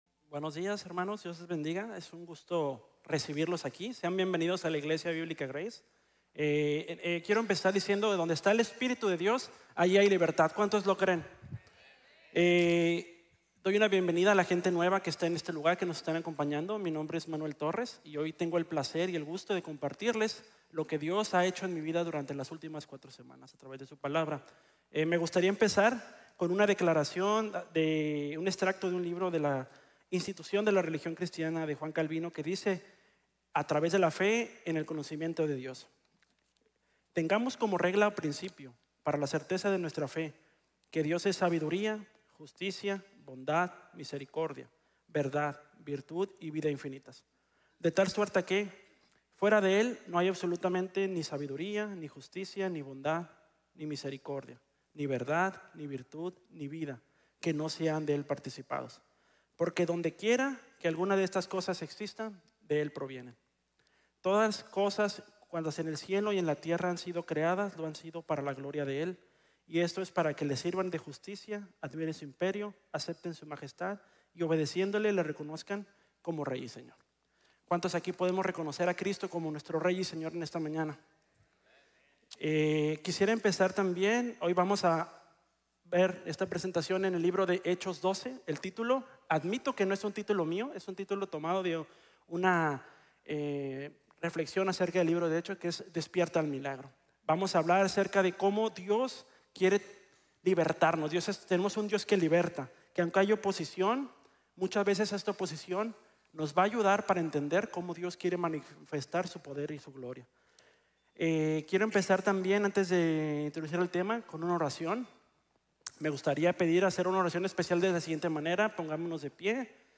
Despierta al Milagro | Sermon | Grace Bible Church